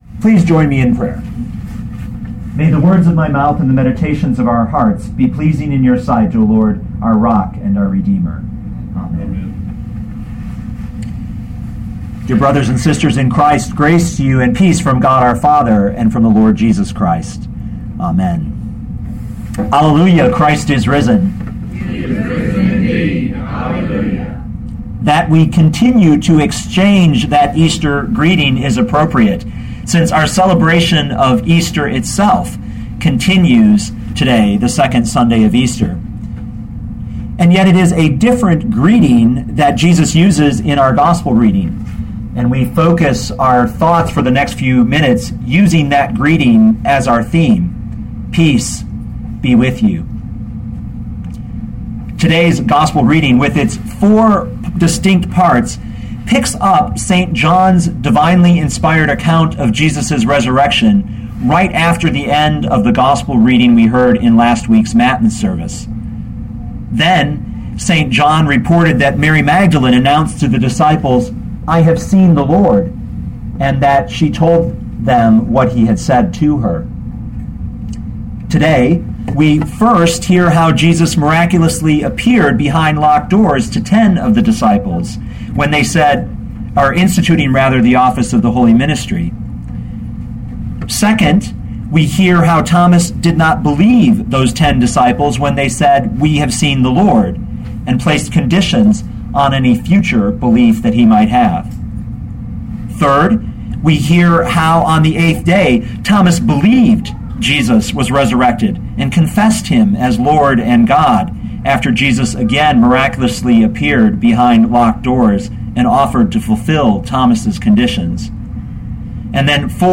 2012 John 20:19-31 Listen to the sermon with the player below, or, download the audio.